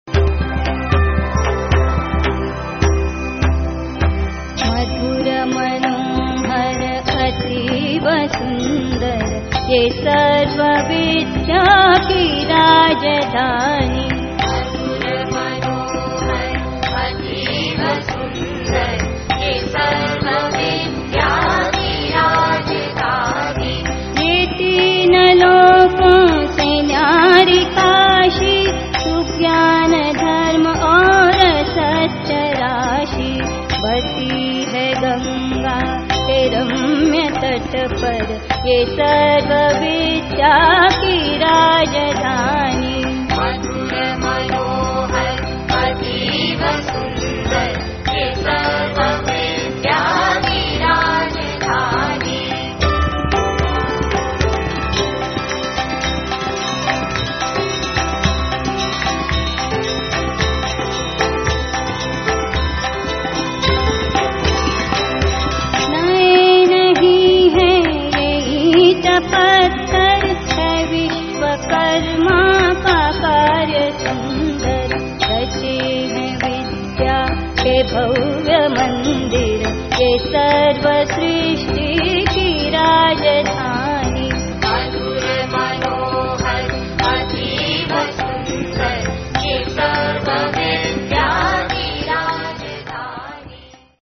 The CD is prepared by the students,teachers and accompanist of the Dept. of Vocal Music with the financial aid by the University. The Kulgeet, in CD is sung three times-One is Girls voices, one in boys voices and and one in mixed voices by the girls students and boys students who wish to learn Kulgeet.